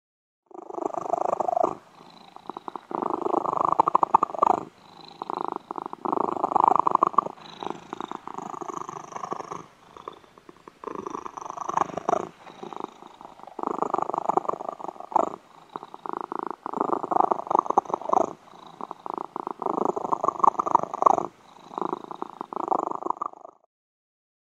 Котенок сладко мурлычет и дремлет